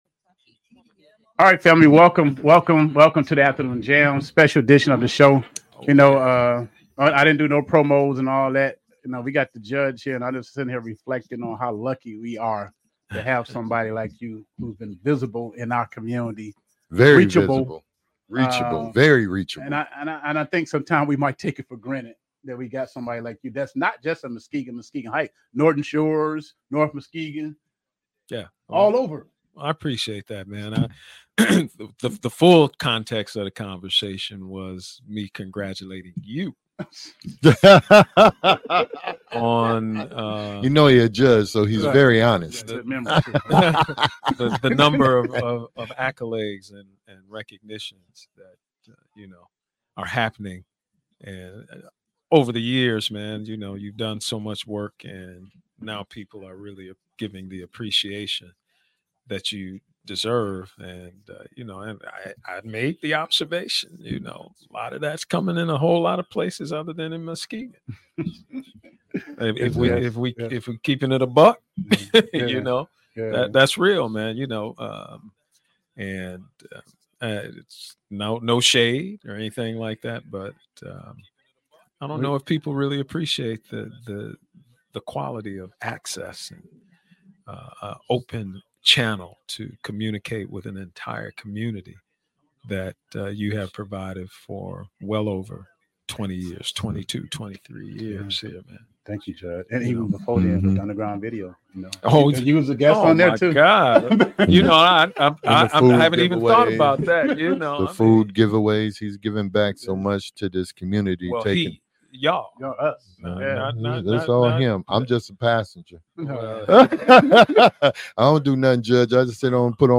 Interview with Esteemed Judge Pittman